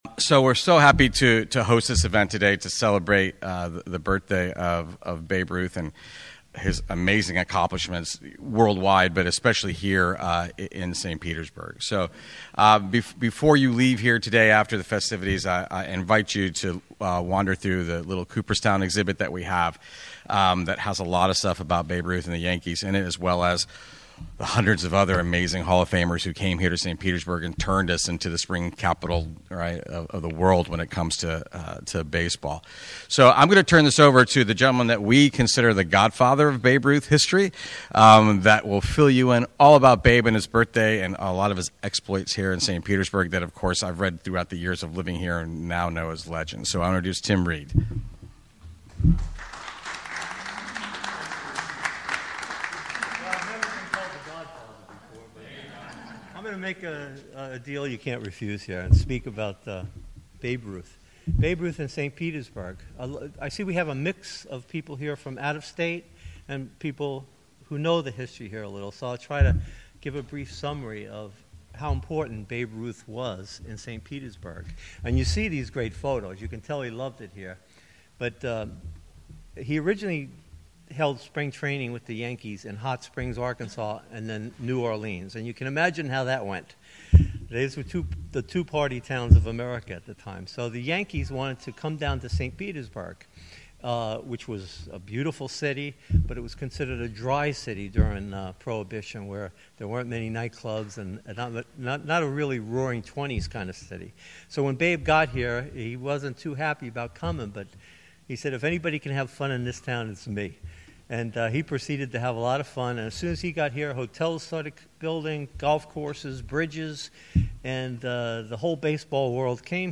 Babe's Birthday Party! Live at St. Petersburg Museum of History 2-7-25
Live broadcast.